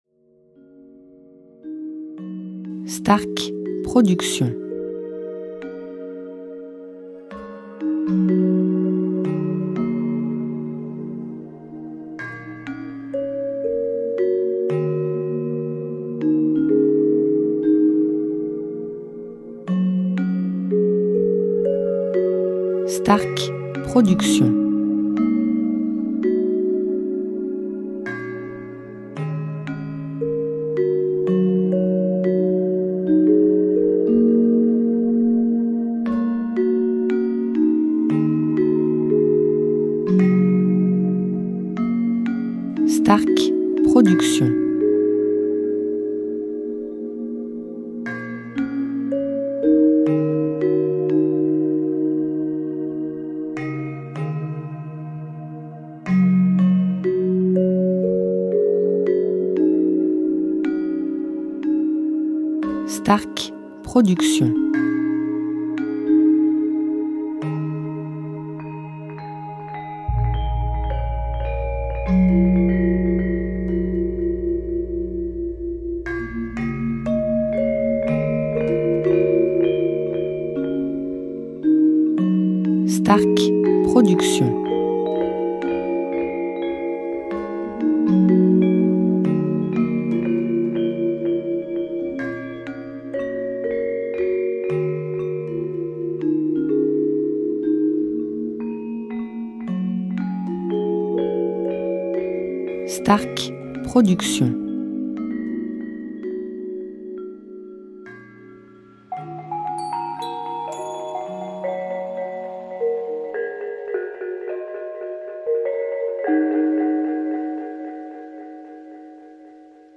style Asie Balinais durée 1 heure